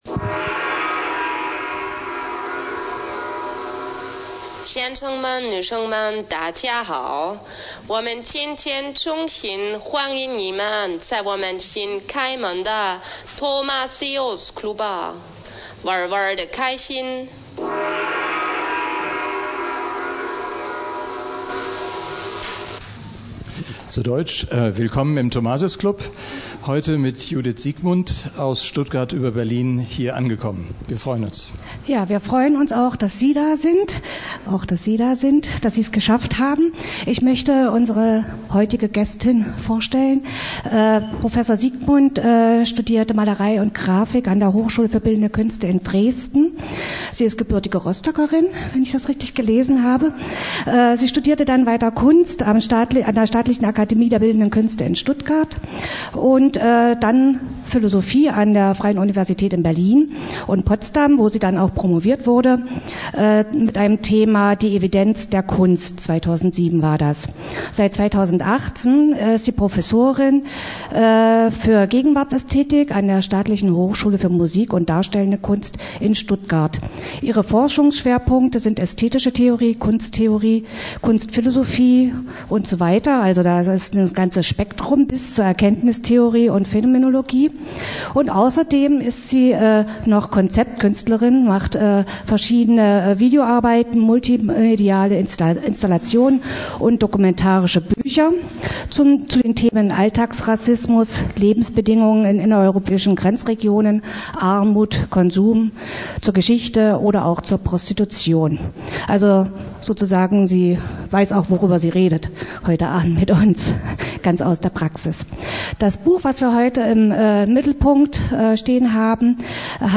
12. Februar 2020 (20:00 Uhr – Café Alibi)